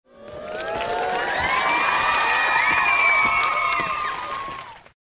Clapping Sfx Sound Button - Free Download & Play